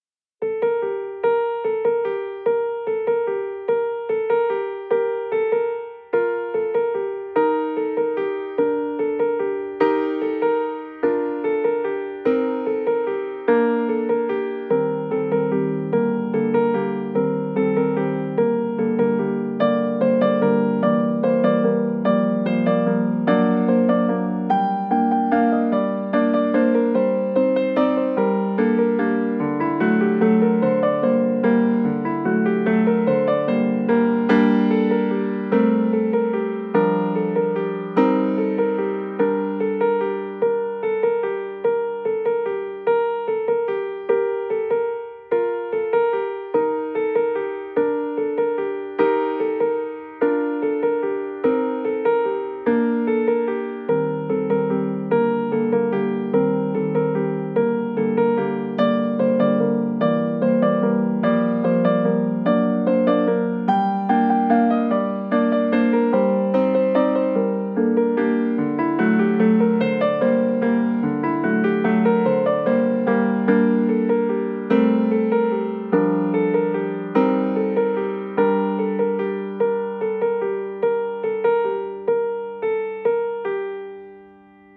aux sonorités vibrantes et magiques